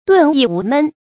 遁逸無悶 注音： ㄉㄨㄣˋ ㄧˋ ㄨˊ ㄇㄣˋ 讀音讀法： 意思解釋： 見「遁世無悶」。